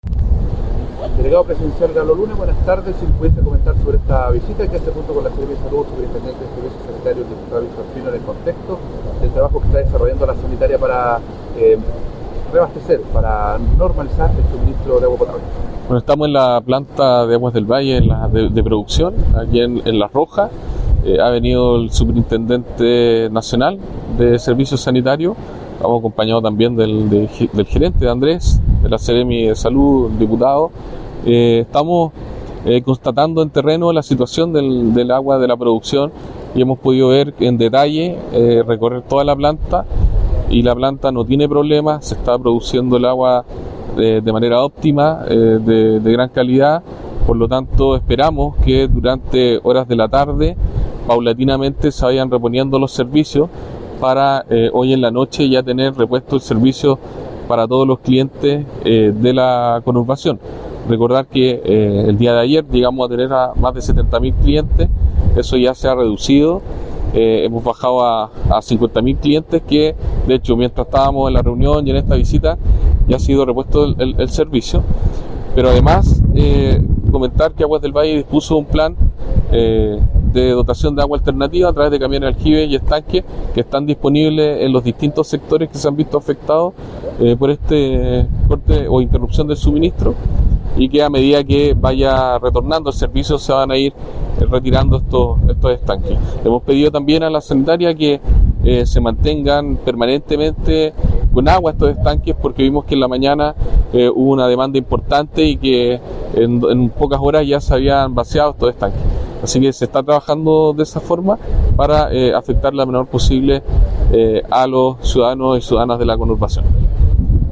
La autoridad regional dijo que
NORMALIZACIN-AGUA-Delegado-Presidencial-Regional-Galo-Luna-Penna.mp3